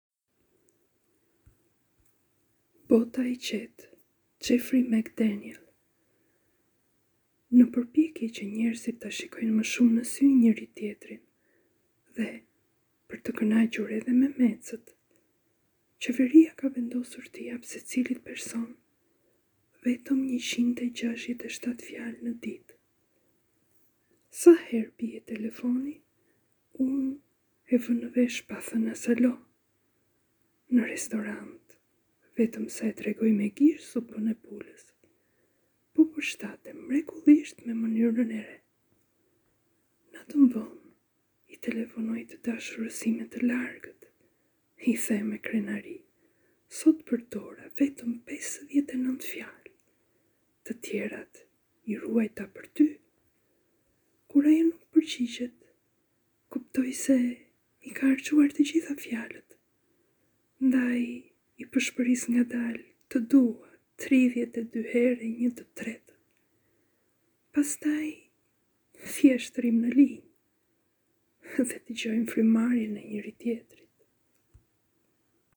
Poezia e lexuar